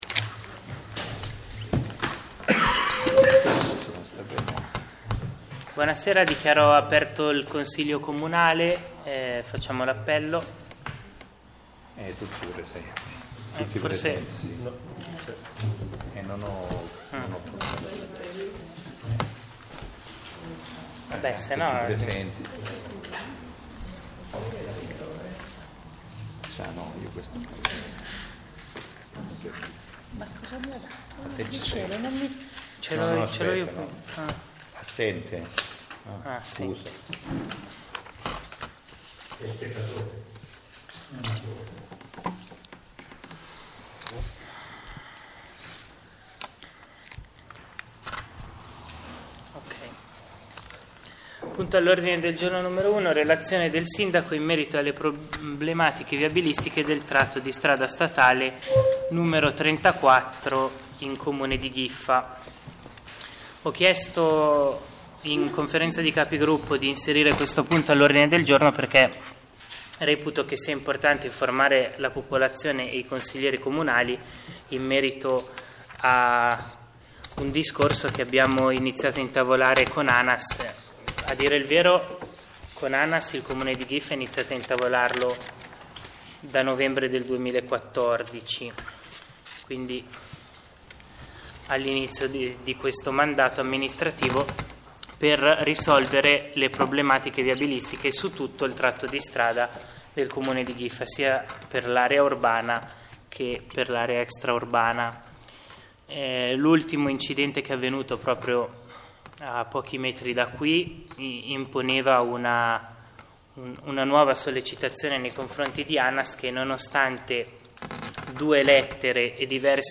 Consiglio comunale del 07.10.2016